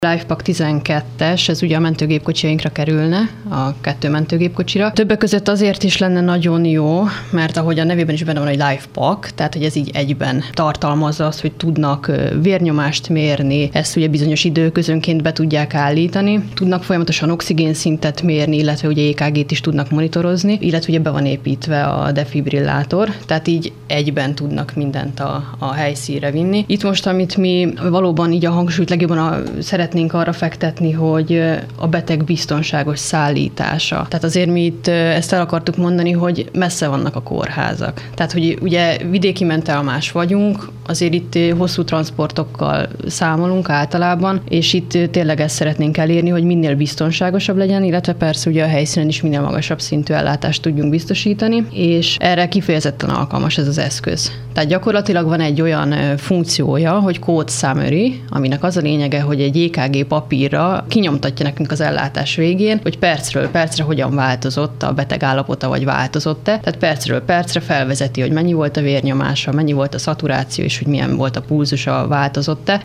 Hírek